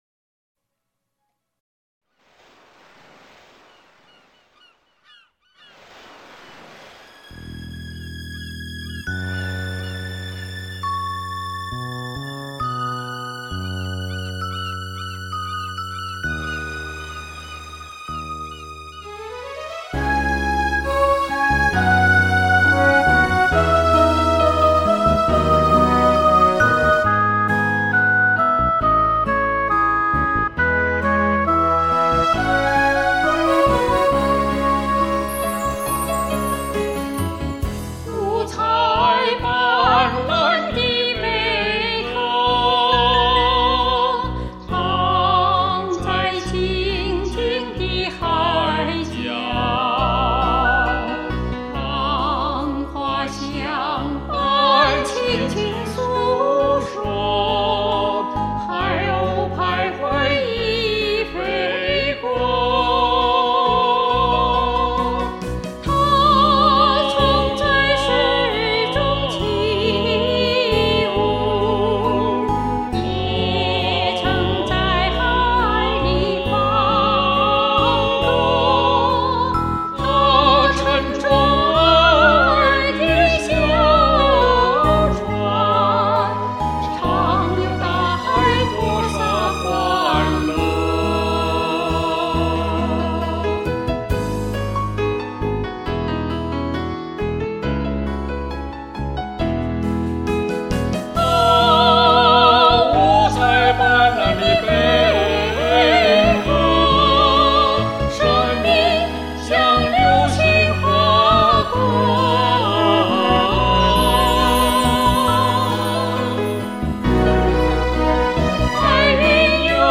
二重唱版！她的歌声如此美妙，音乐形象把握准确，演唱情绪很到位，细腻中不乏激情, 十分精彩。